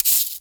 SingleHit_QAS10773.WAV